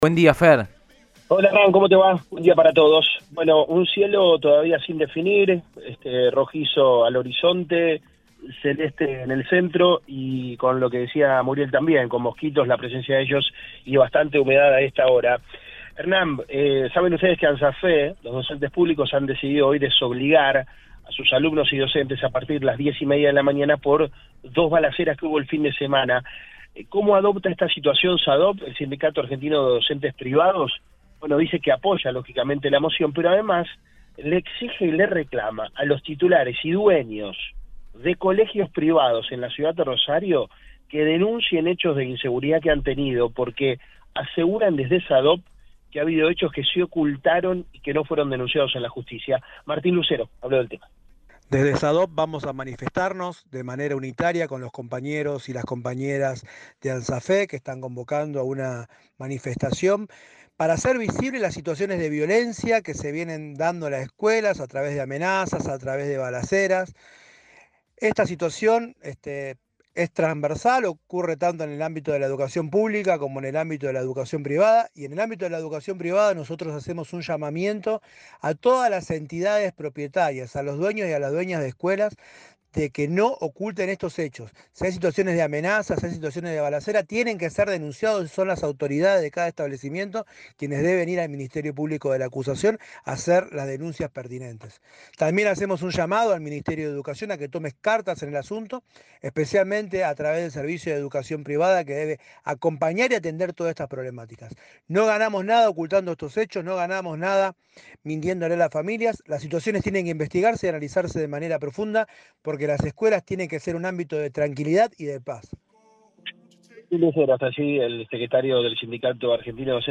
dialogó con el móvil de Cadena 3 Rosario, en Radioinforme 3, y planteó su acompañamiento al gremio de docentes públicos.